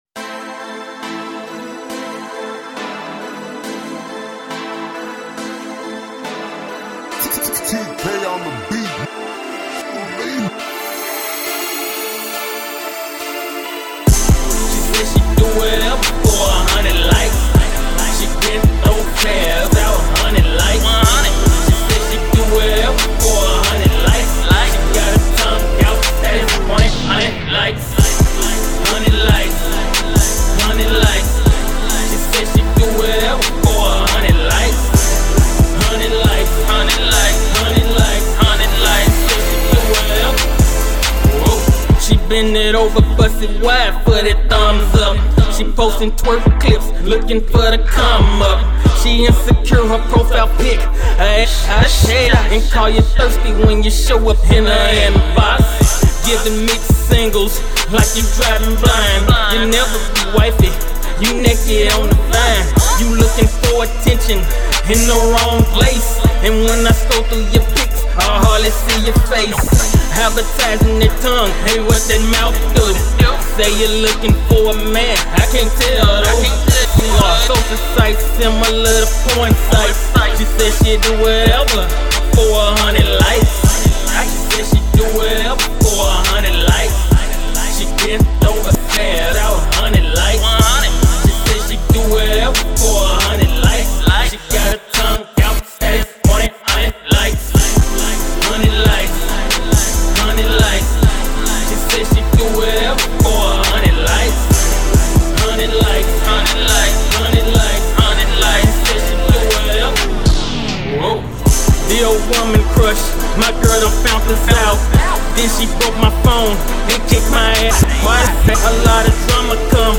Description : Uptempo Club Track Prod.